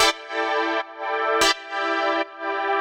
GnS_Pad-MiscB1:2_170-E.wav